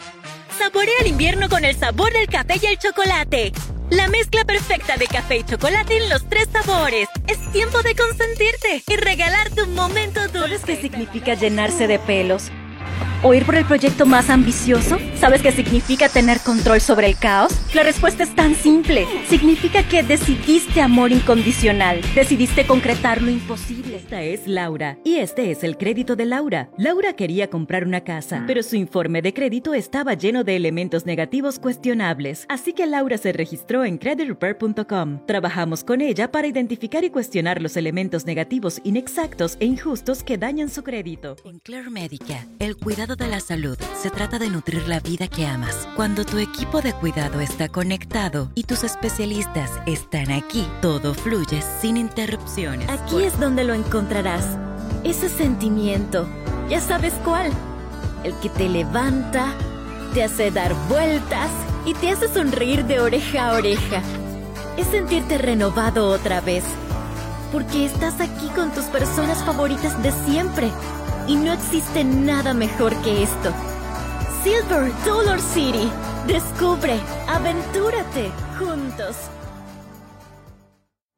Voice Over, locutora profesional, mi voz será la voz de tu marca o proyecto, con más de 15 años de experiencia. Voz femenina para Internet,TV, Radio y más.